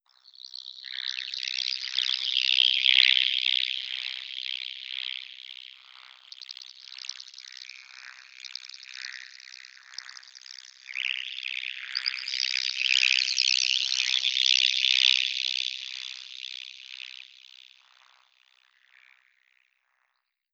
alien_sounds